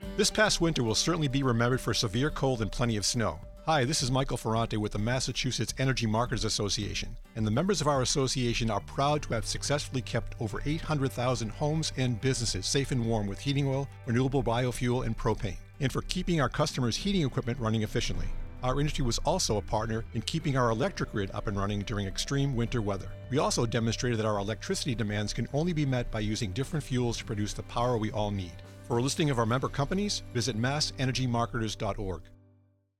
• March 2026 Heating Oil Industry Radio: 30 Second Spot